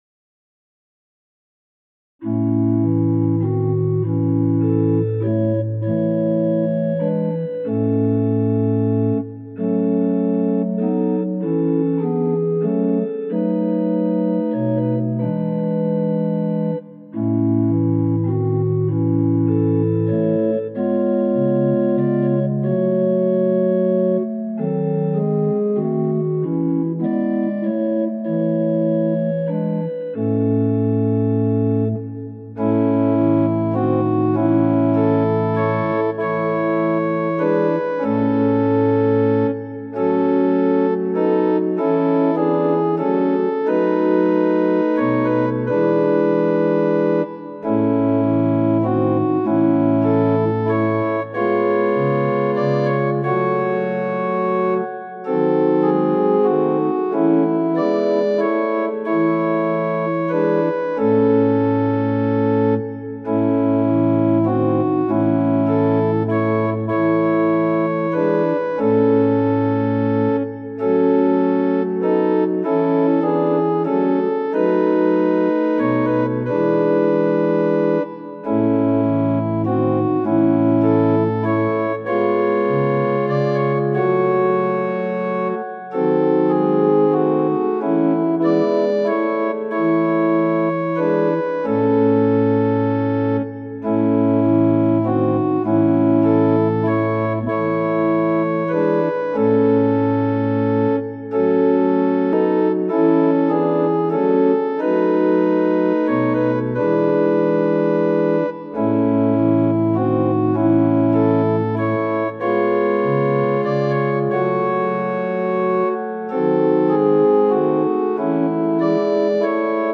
♪賛美用オルガン伴奏音源：
・柔らかい音色)部分は前奏です
・はっきりした音色になったら歌い始めます
・節により音色が変わる場合があります
・間奏は含まれていません
Tonality = A Pitch = 440
Temperament = Equal